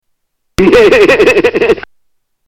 Howard Cosell laugh